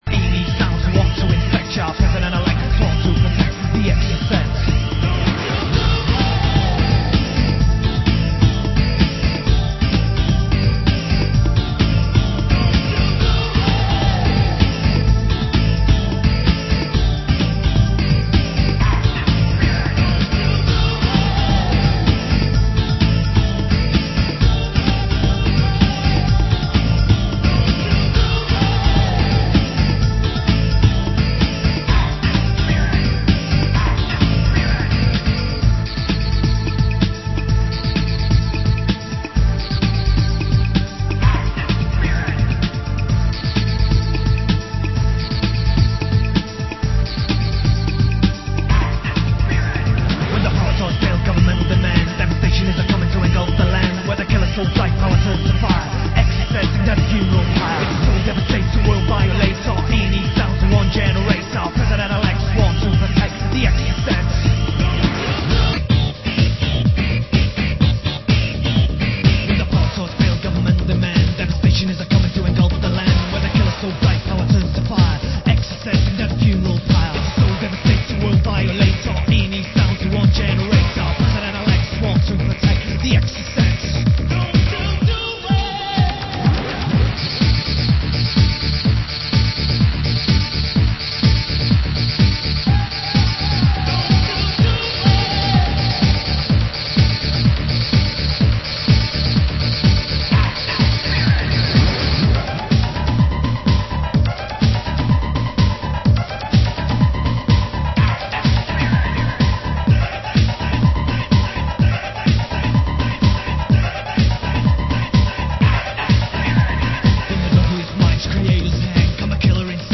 Format: Vinyl 12 Inch
Genre: Break Beat